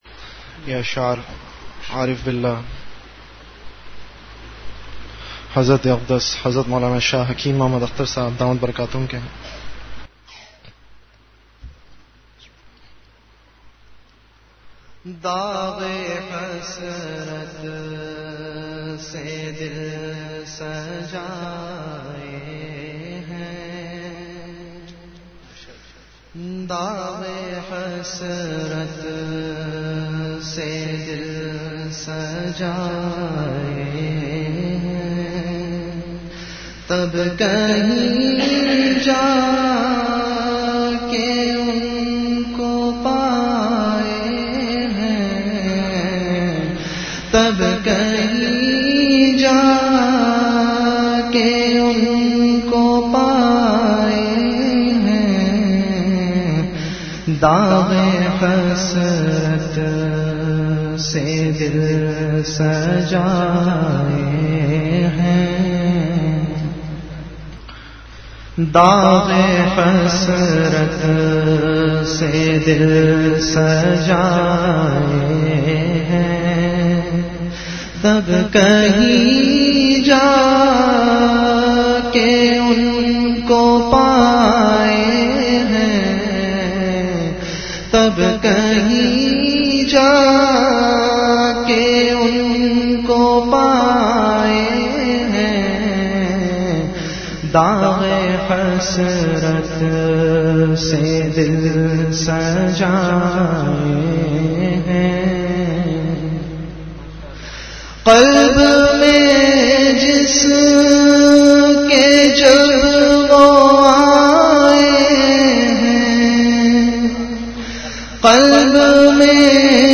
Delivered at Home.
Ashaar